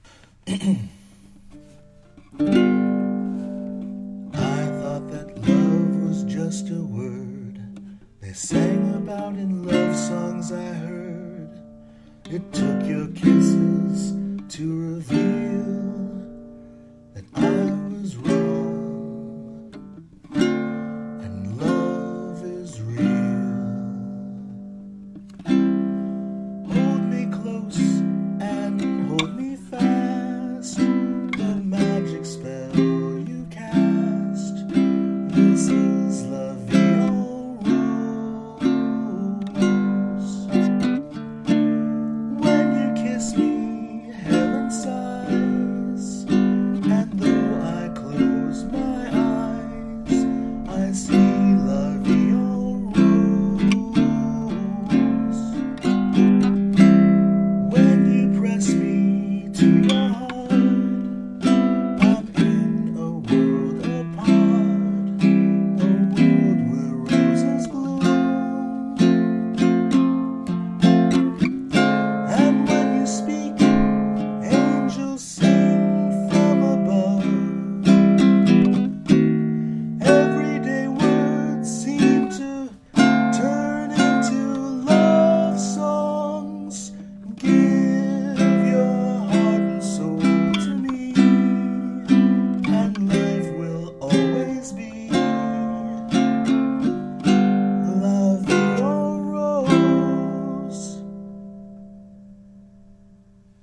Now, about that album of ukulele backed standards…